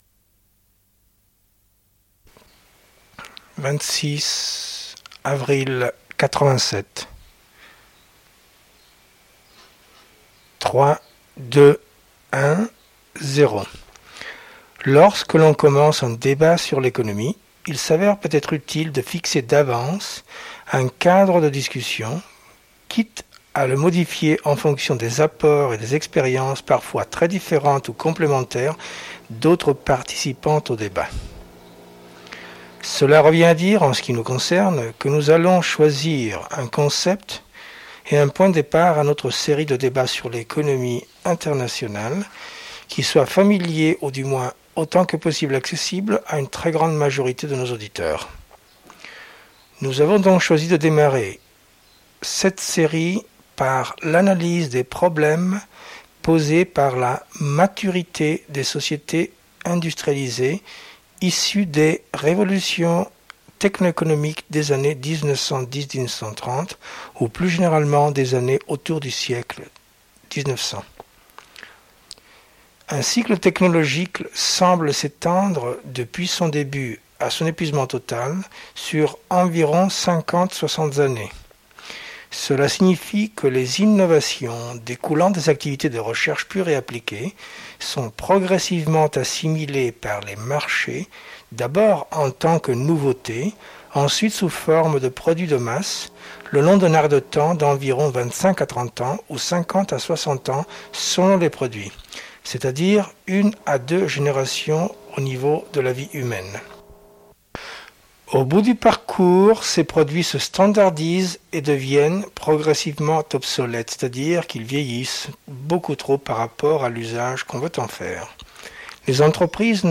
Le groupe produit des émissions sur Radio Zones pendant les années 1980.